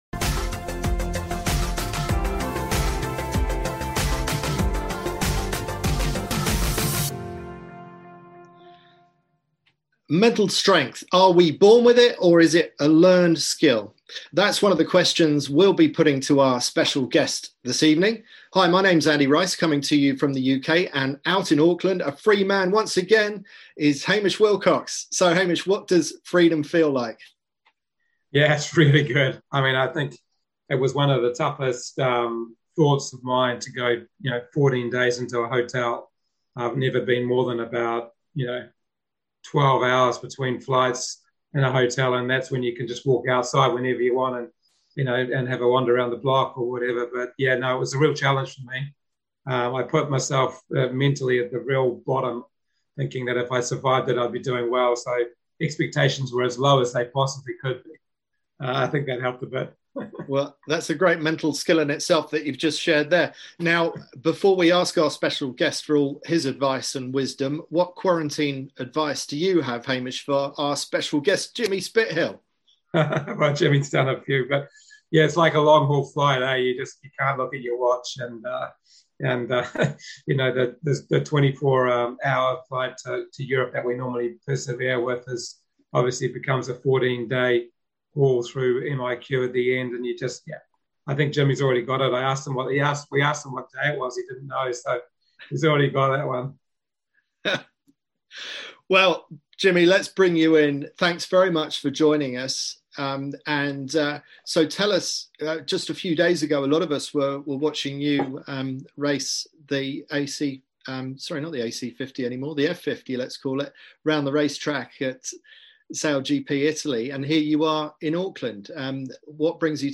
Our weekly Q&A sessions on Zoom, to answer your burning questions and enlighten you on your Road To Gold